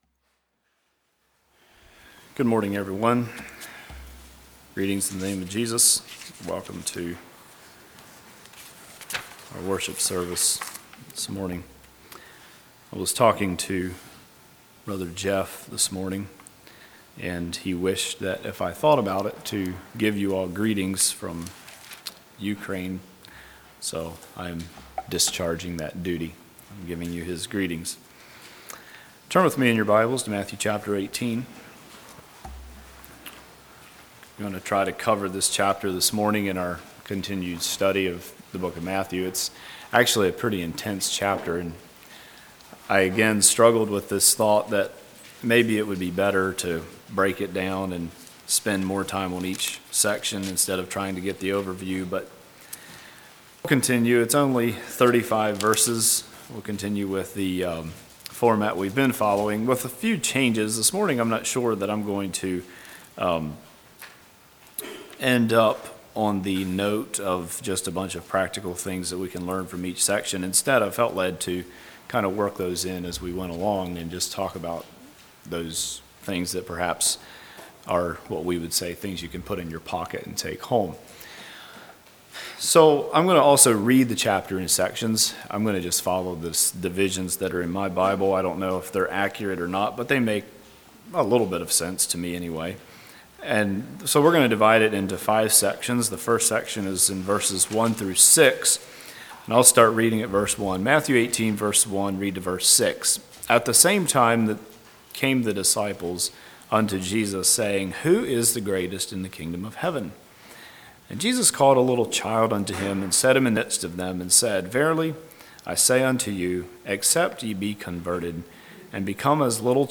Congregation: Darbun
Sermon